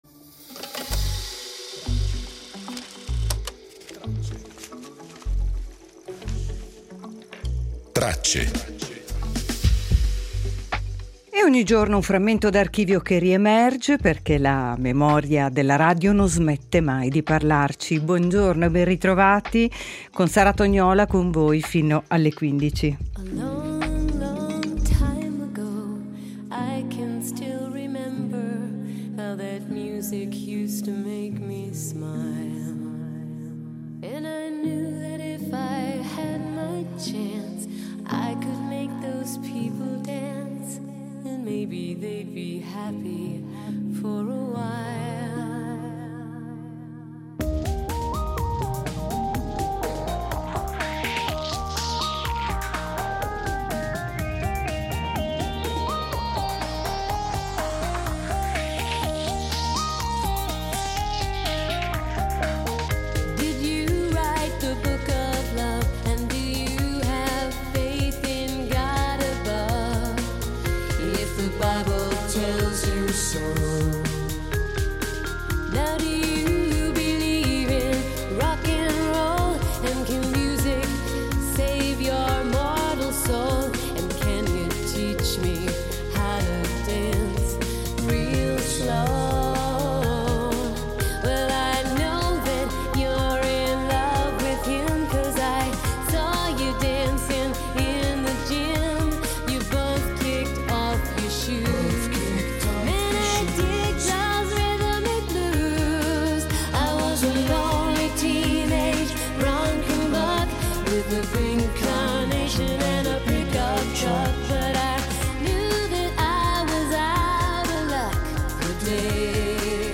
Attraverso materiali dell’archivio del Radiogiornale, riascoltiamo le testimonianze del giorno successivo alla repressione delle proteste studentesche: voci che raccontano lo smarrimento, il silenzio e la portata di un evento che ha segnato la storia contemporanea.
Dallo sceneggiato radiofonico del 1982 emerge il ritratto di una figura enigmatica e straordinaria, simbolo di un’ingegnosità senza tempo e di una curiosità che ancora oggi continua a interrogarci.